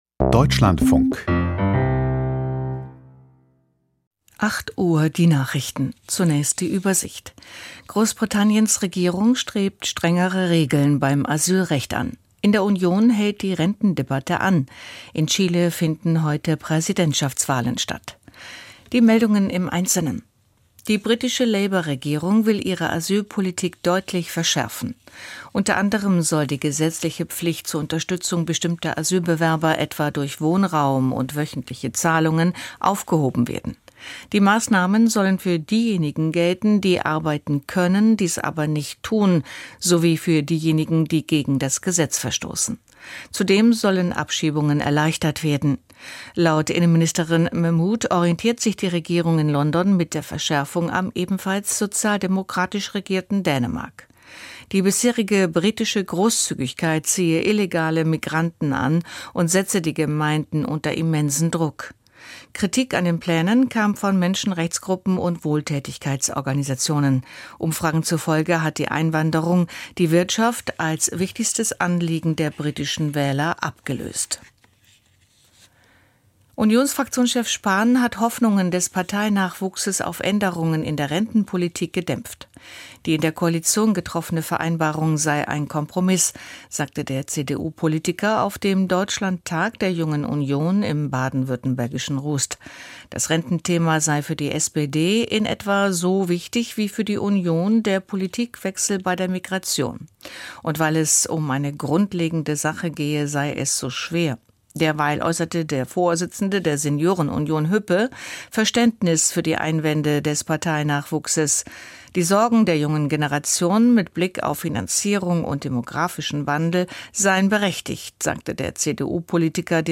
Die Nachrichten vom 16.11.2025, 08:00 Uhr